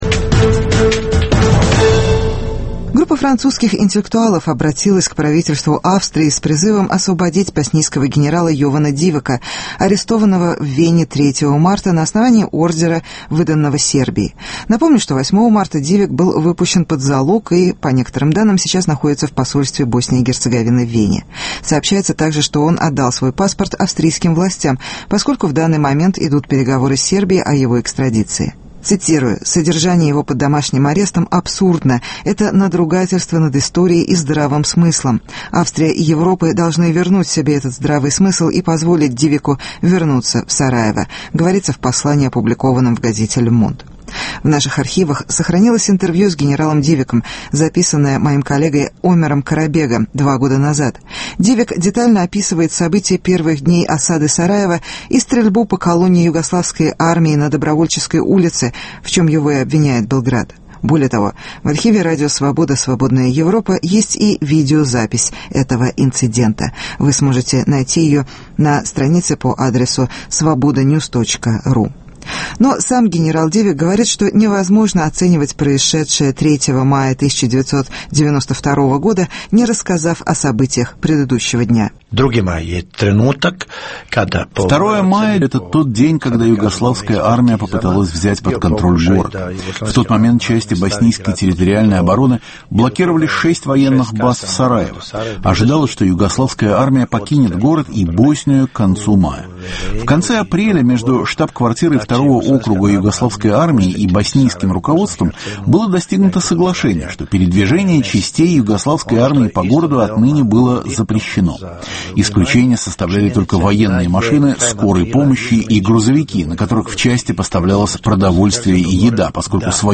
Генерал Дивяк о мае 1992 года в Сараево - архивное интервью